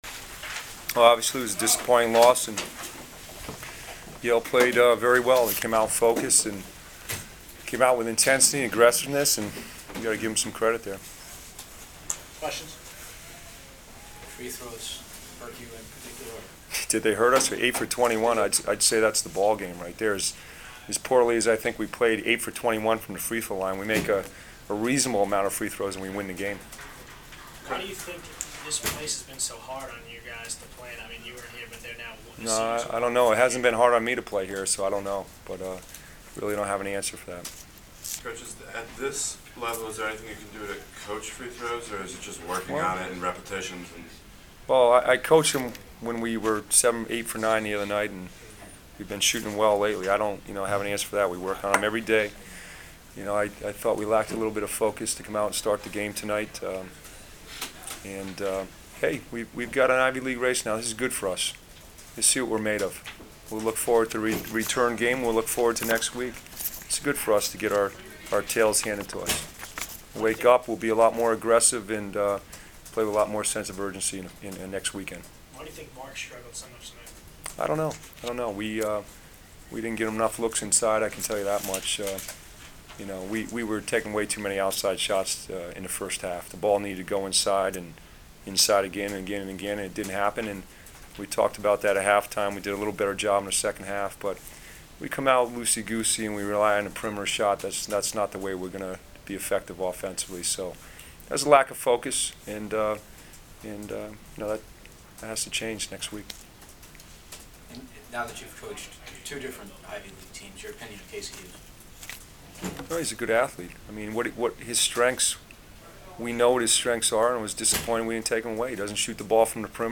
Postgame audio